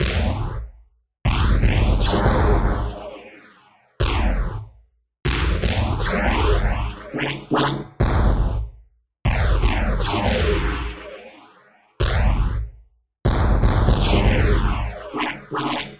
ominous.mp3